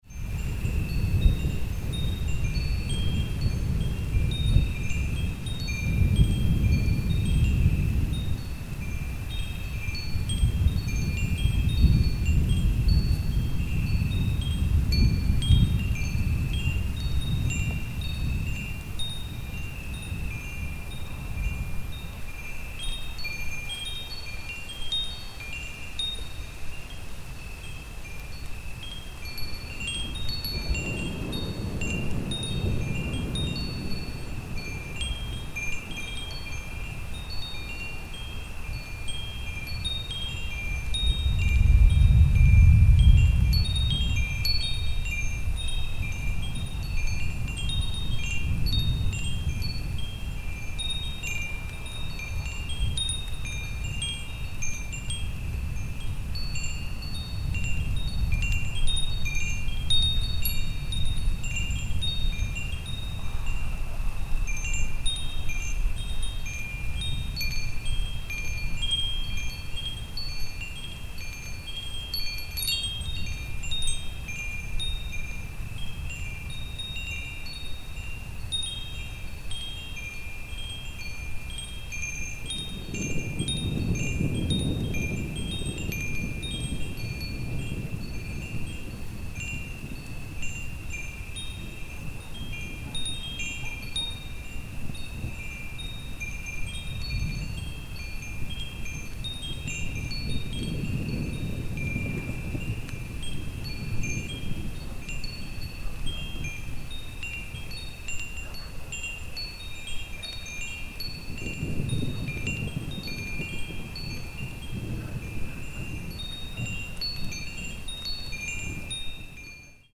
Thunderstorm-and-wind-chimes.mp3